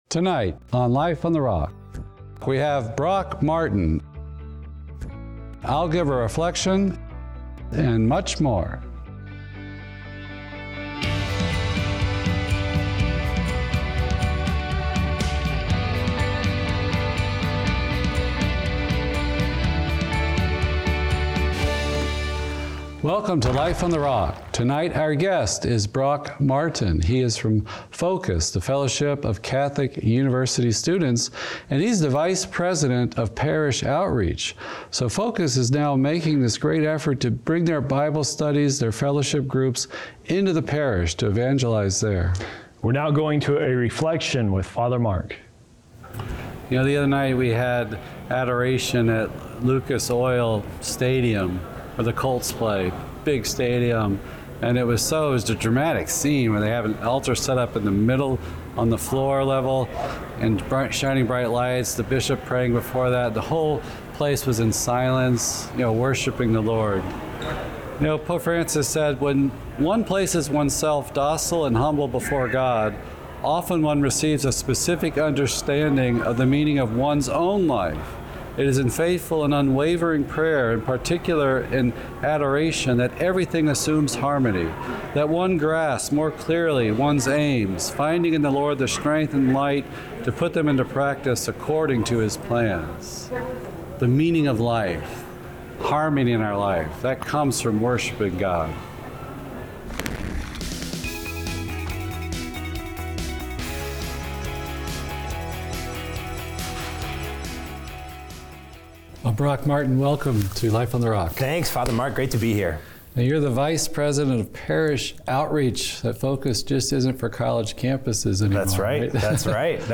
Interviews, spiritual insights from the friars, music videos and much more.